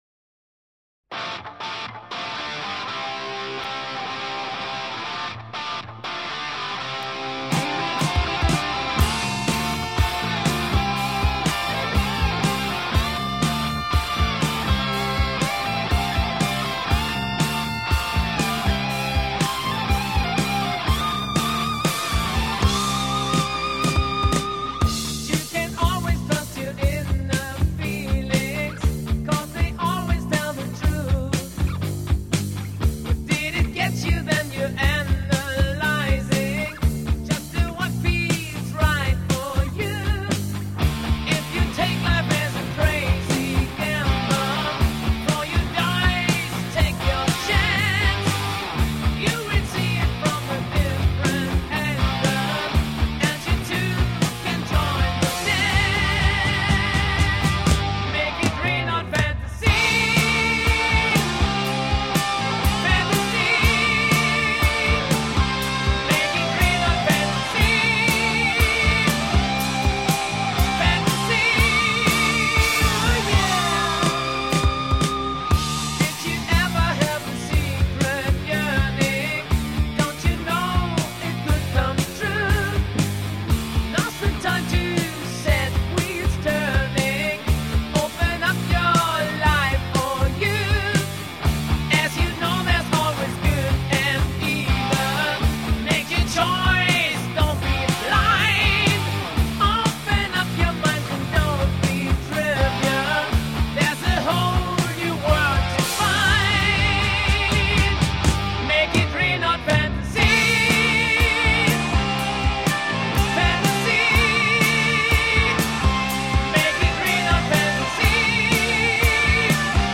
HardRock, HeavyMetal